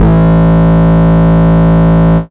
Mood Bass (JW2).wav